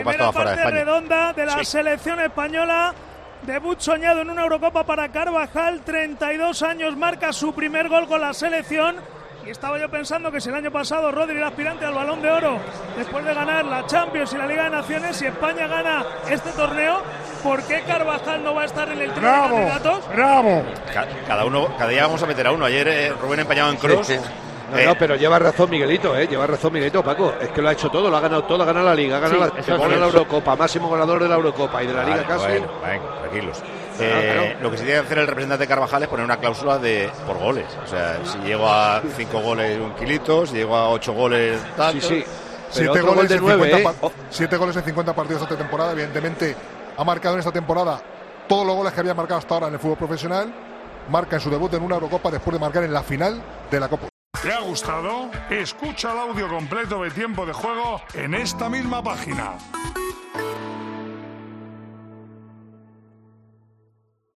El colegiado del partido señaló el camino a los vestuarios y Miguel Ángel Díaz, durante la retransmisión de Tiempo de Juego, realizó una curiosa petición.
A lo que Manolo Lama respondía: "¡Bravo, bravo!".